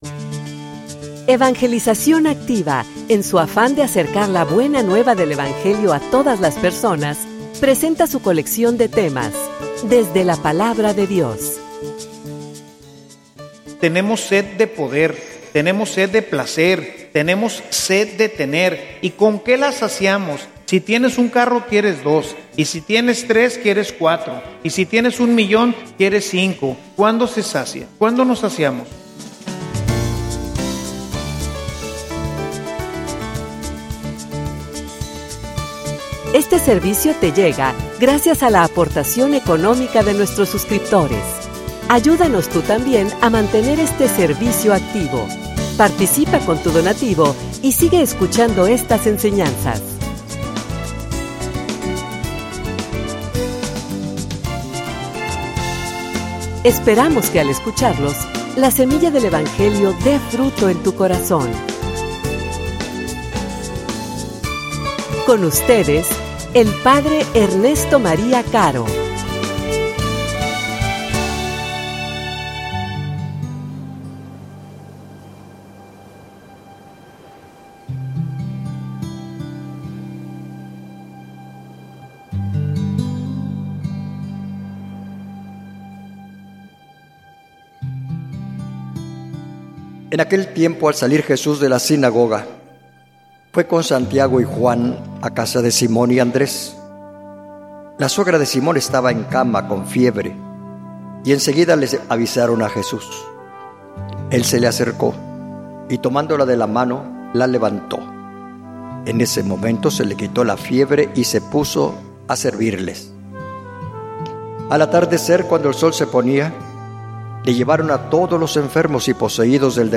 homilia_Conocer_para_amar.mp3